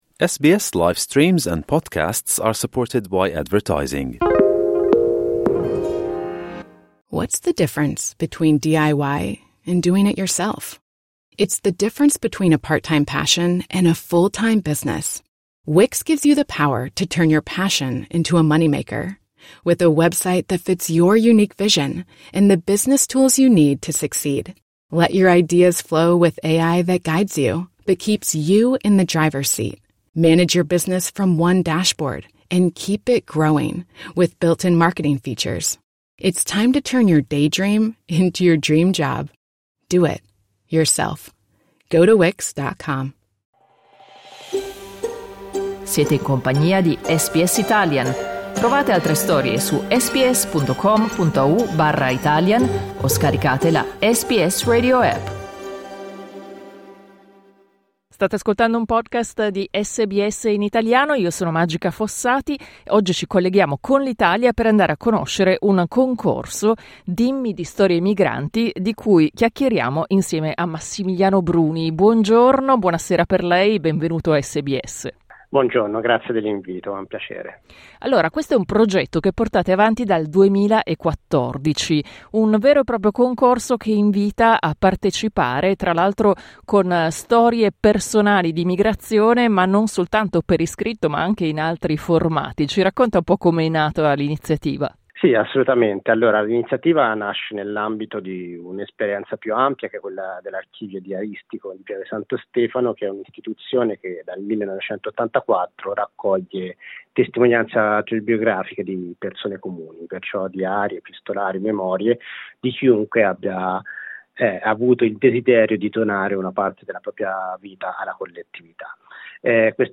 SBS in Italiano